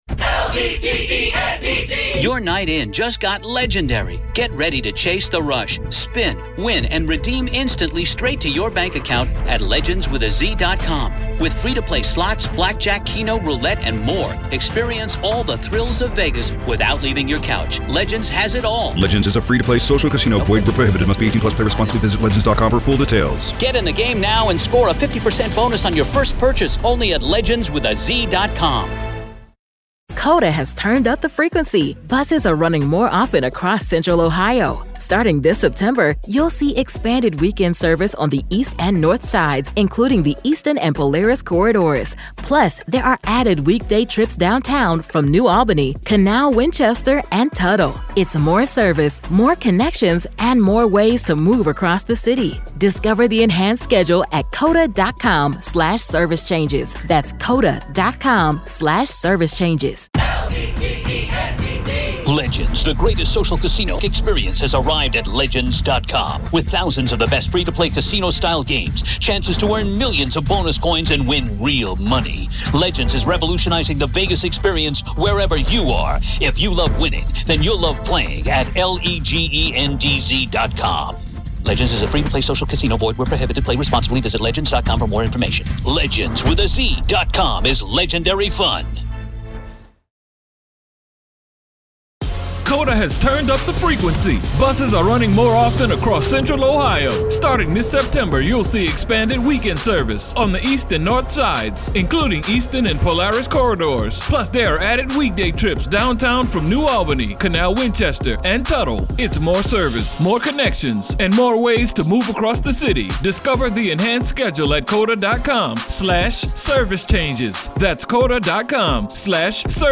From his extreme personality disorders to the eerie way he could turn lost young people into devoted followers, this conversation dives deep into the psychology of control. Was Manson any different from gang leaders, military commanders, or radical influencers throughout history?